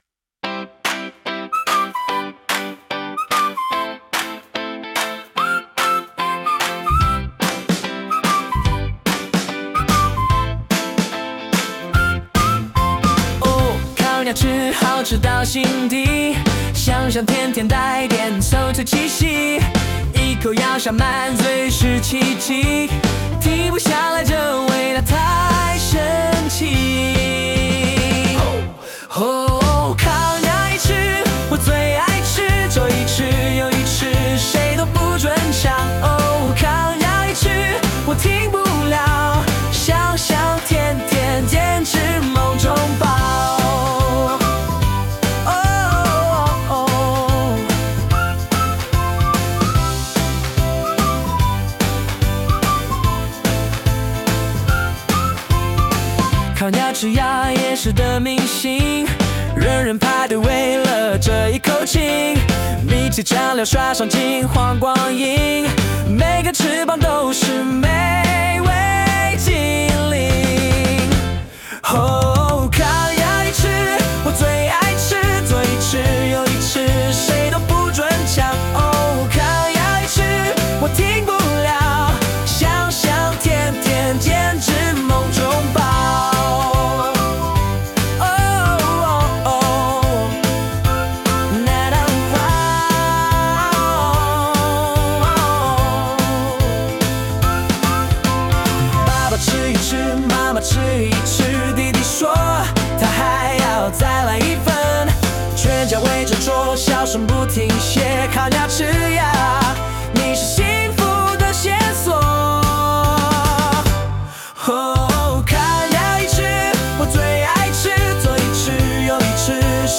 最近發現AI音樂還蠻好玩的，搞了一個來玩玩
心情嘛，既然是吃這種好事，當然是選充滿活力的啦。
然後取個名，選一下男聲或女聲，我覺得這個女聲還蠻好聽的（但我最後還是選了男生，因為我覺得比較搞笑
音樂節奏還滿好聽的
不過抖音味蠻重的